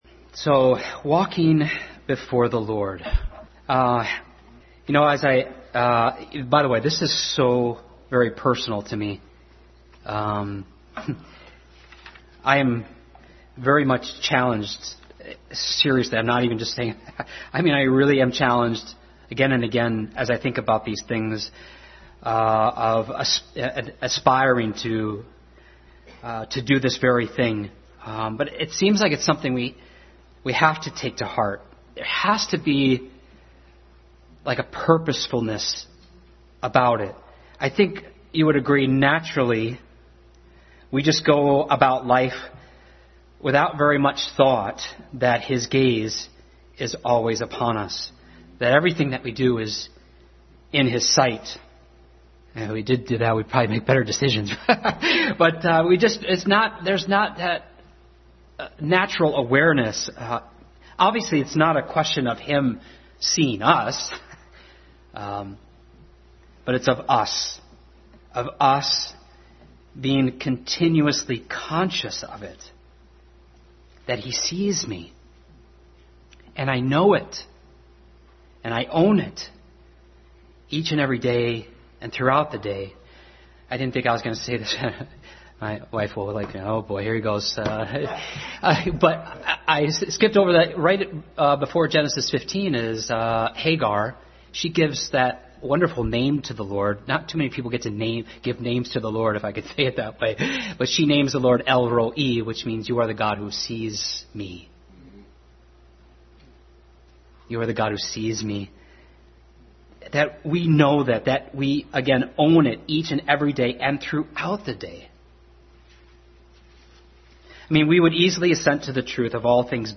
Passage: Genesis 17, Philippians 4:4-6, Ephesians 1:15-21 Service Type: Family Bible Hour